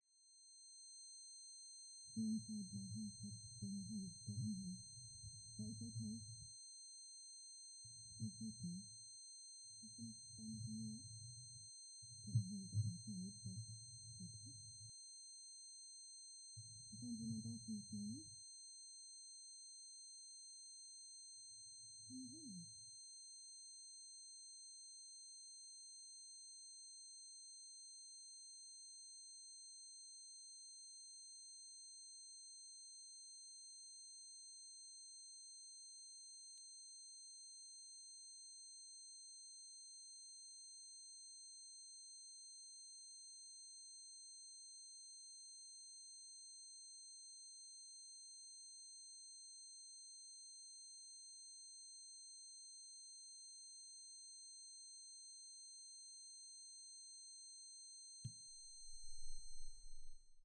Здесь можно послушать и скачать примеры гула, звона, шипения и других фантомных шумов, которые некоторые люди воспринимают субъективно.
Звон в голове во время головной боли